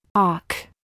Play Audio alk Play Audio Play Audio Play Audio Play Audio No audio provided /ŏk/ Play Audio alk Other Spellings: OCK OC clear alk Add a new comment Cancel reply You must be logged in to post a comment.
ALK-walk-phoneme-name-AI.mp3